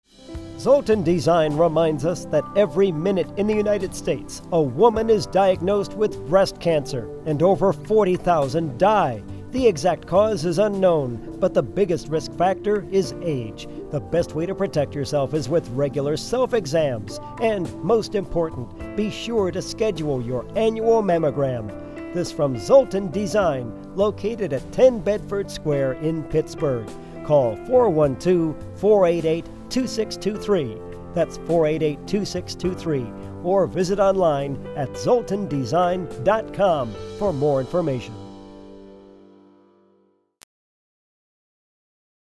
Zoltun Design sponsored a Public Service Announcement for breast cancer awareness on WKQV radio.